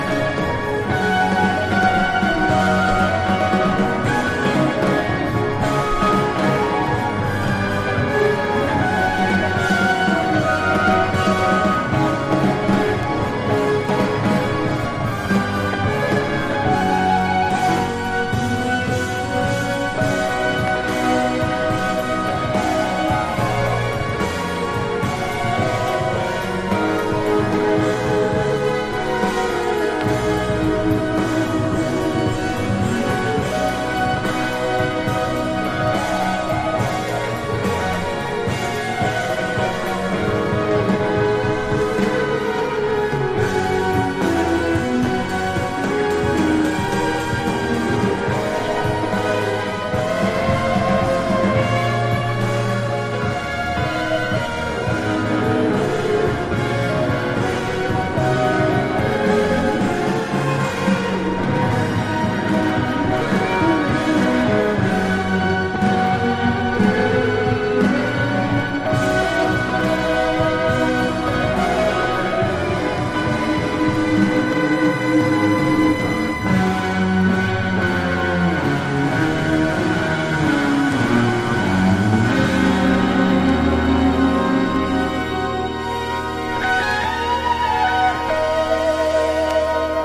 1. 70'S ROCK >
PSYCHEDELIC / JAZZ / PROGRESSIVE